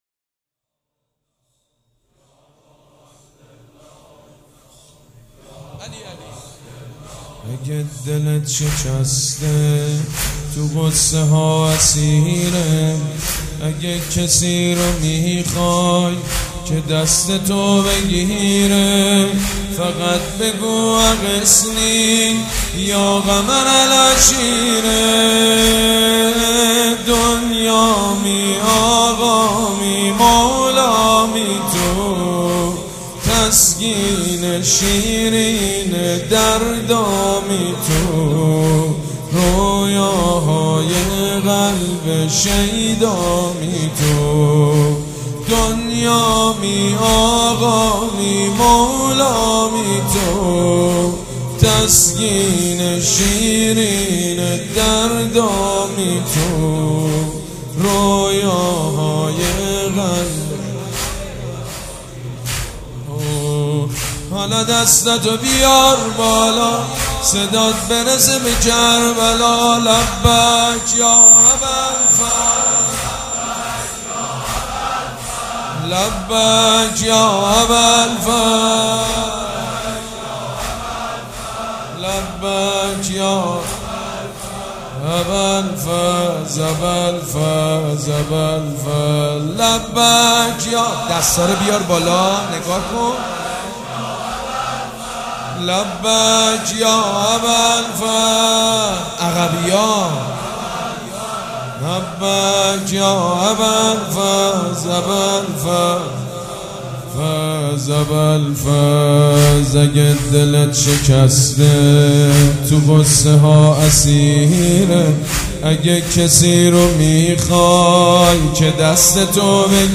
حاج سید مجید بنی فاطمه
وفات حضرت ام البنین(س) 1396
مداح